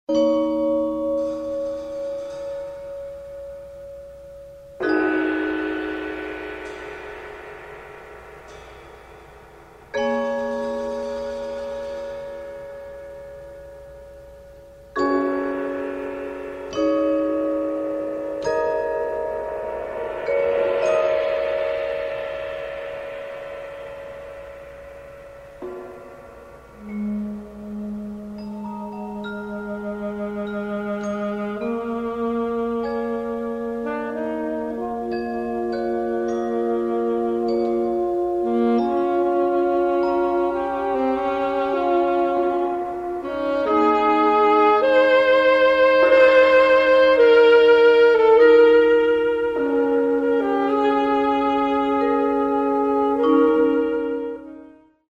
for Two Saxophonists and Three Percussionists
saxophone and Percussion